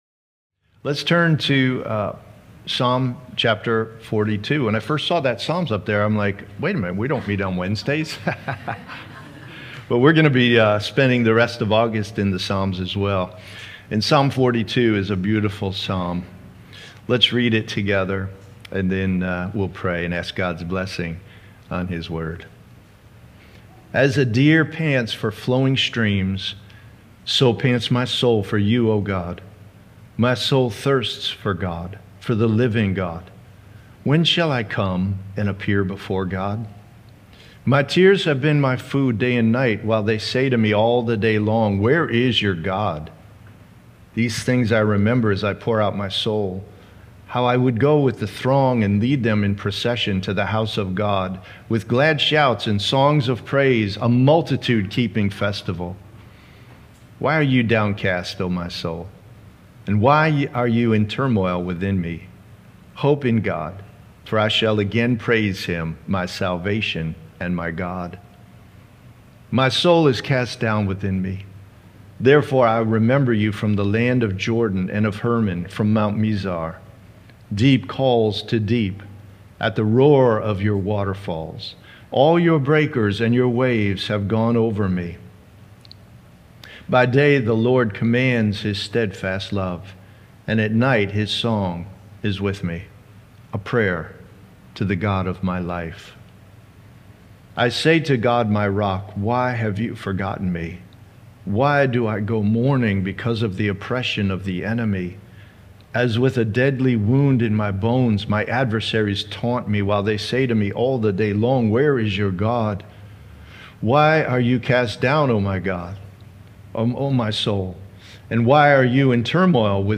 Religion Grace Community Sermon Series Christianity Grace Community Church Painted Post Content provided by Sermon Series, Grace Community Church: Painted Post, and NY.